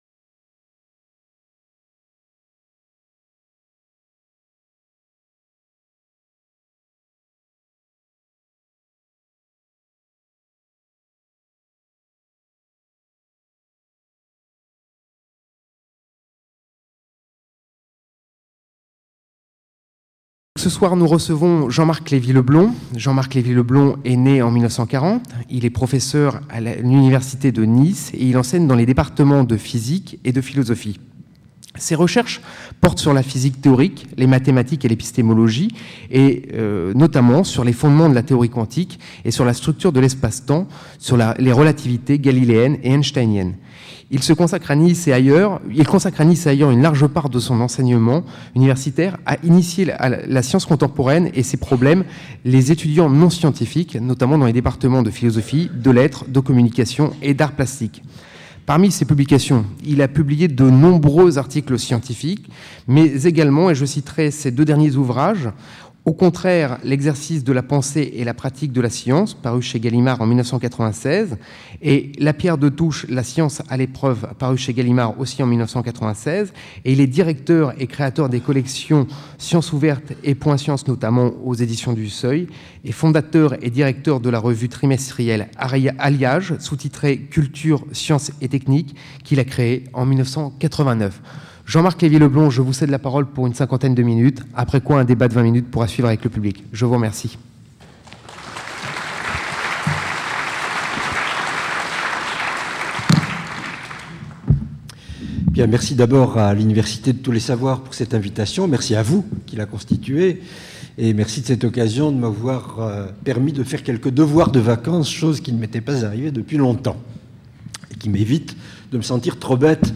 Il n'est pas indifférent que dans ce cycle de conférences sur "tous les savoirs", la question des limites de la connaissance n'ait été posée qu'à la physique.